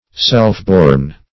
Self-born \Self"-born`\, a. Born or produced by one's self.